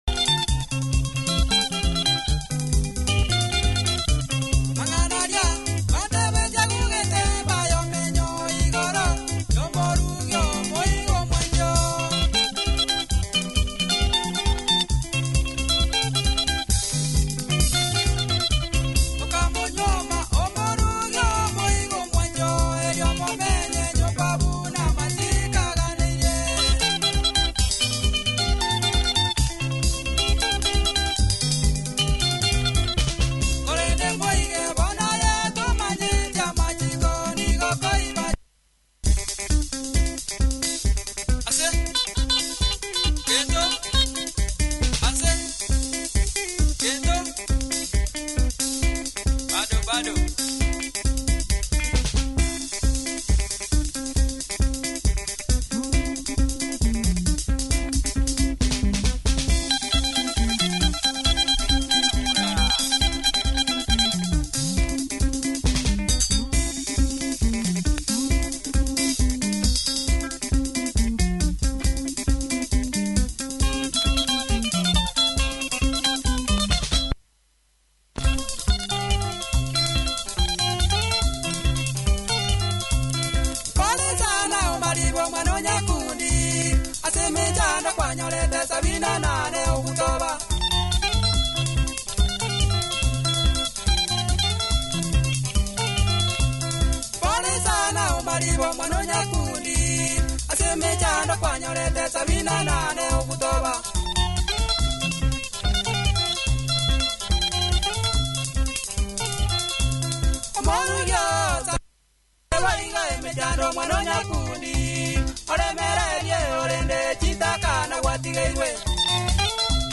Tight Kisii Benga, punchy production good tempo, loud too.